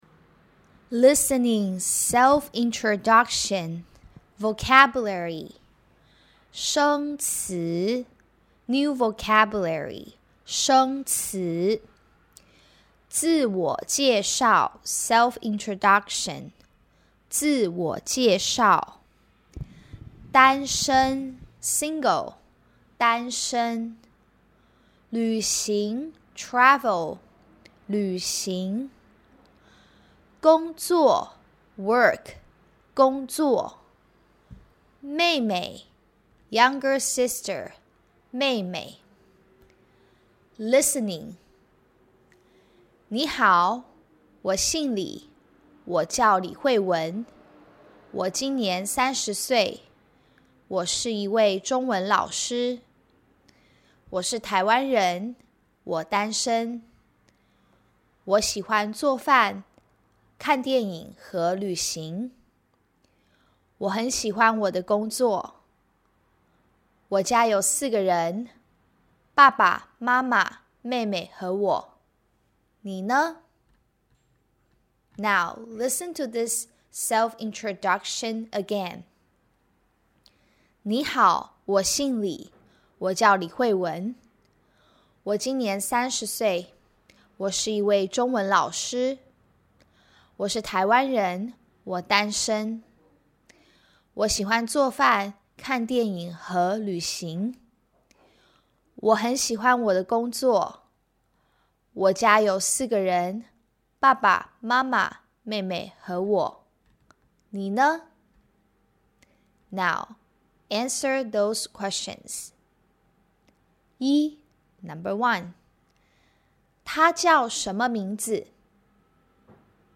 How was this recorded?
You'll hear the monologue twice and you'll have to answer the questions as you hear the questions.